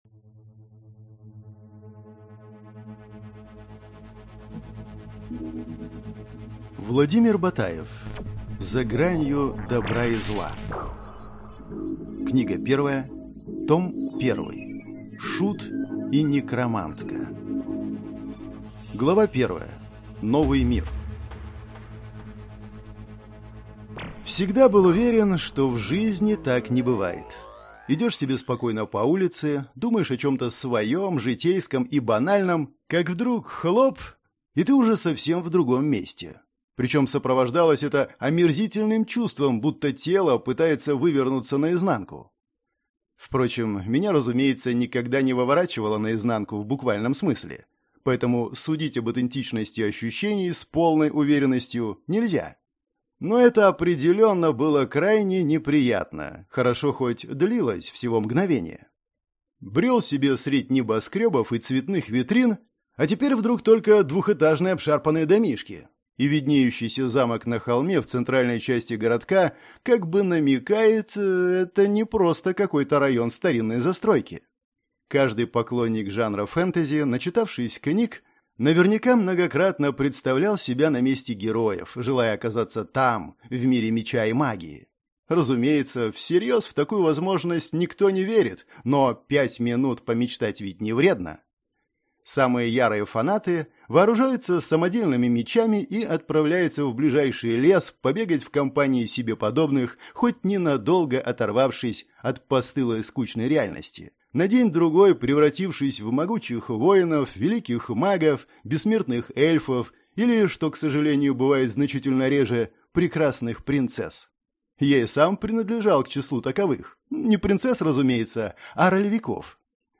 Аудиокнига Книга 1.